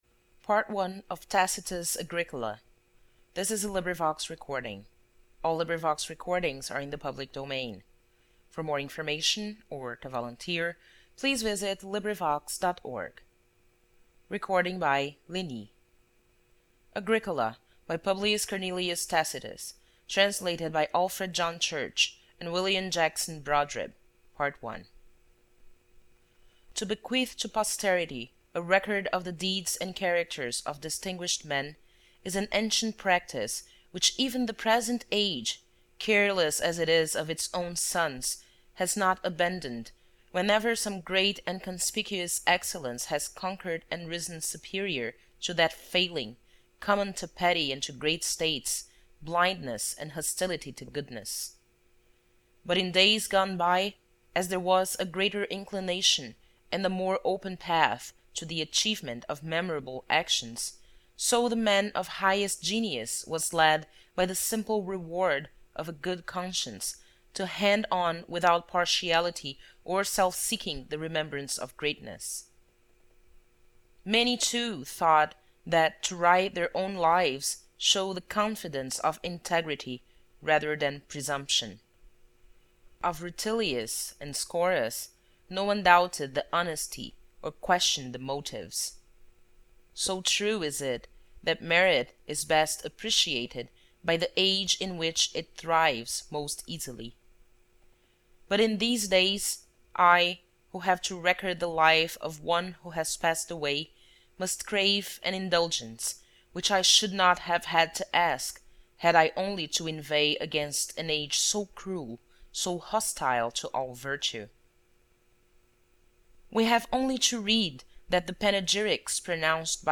ServeToMe: / 8TB-Media2 / Audio Book / Audiobook - Agricola by Publius Cornelius Tacitus